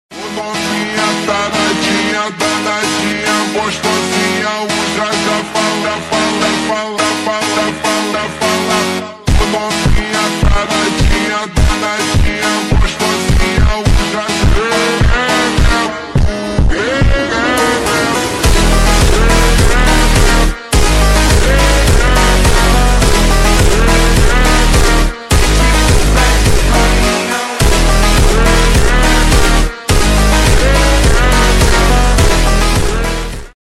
( Slowed )